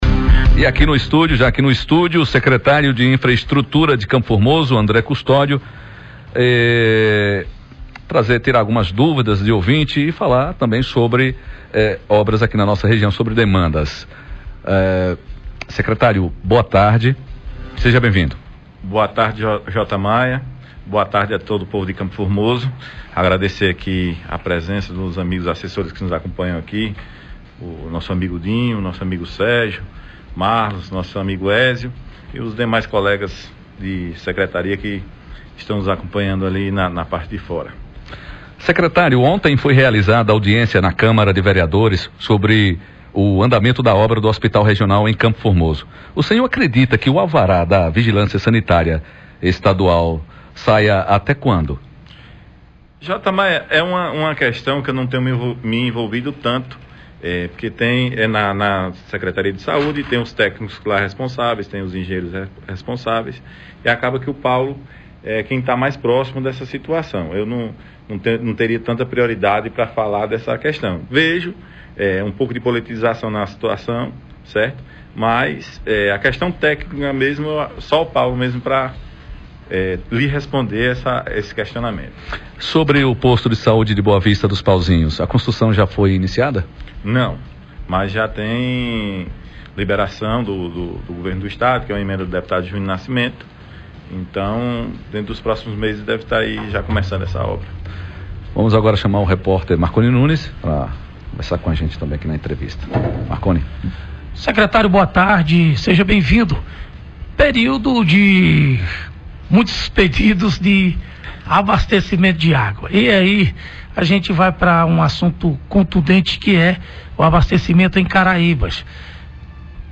Secretário de infraestrutura, André Custódio – Responde à demanda de ouvintes